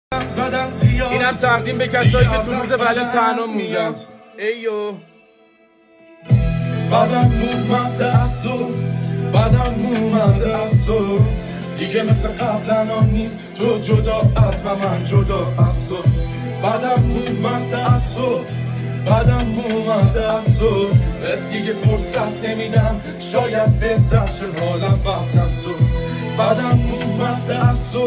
عاشقانه و احساسی